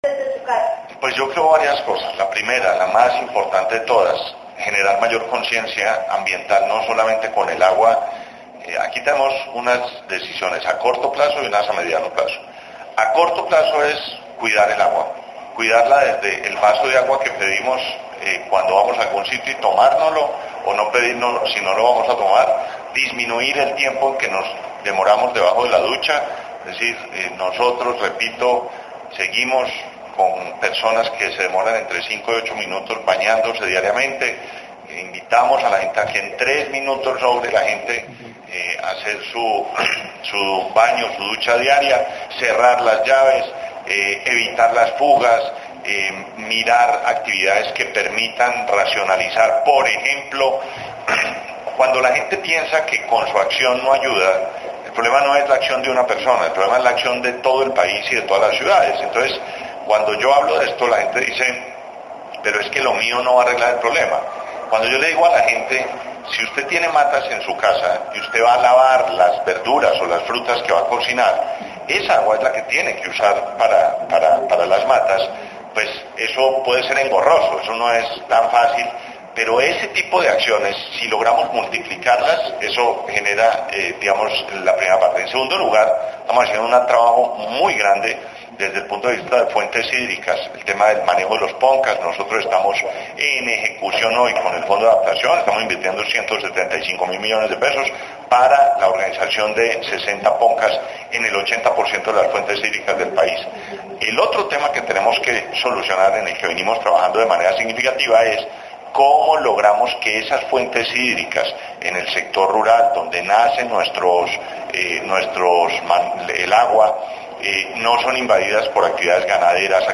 Declaraciones del Ministro de Ambiente y Desarrollo Sostenible, Gabriel Vallejo López audio